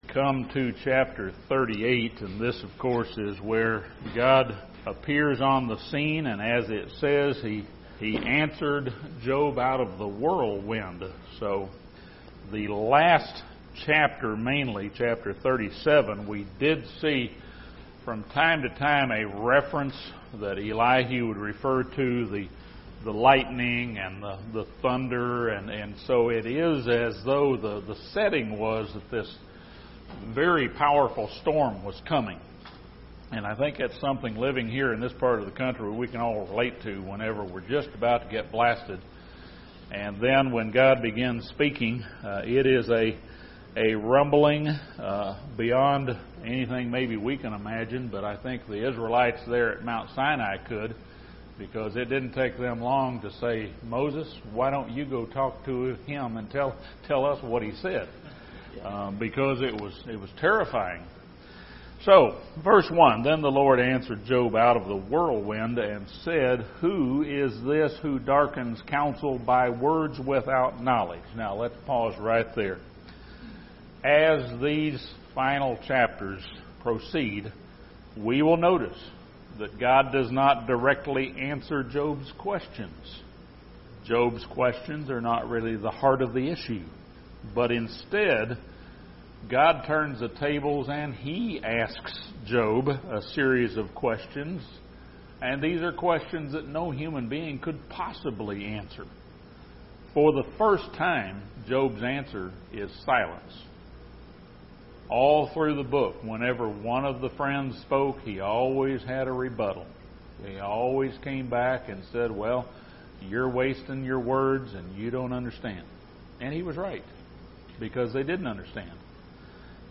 This Bible study surveys the final chapters when God began speaking to Job.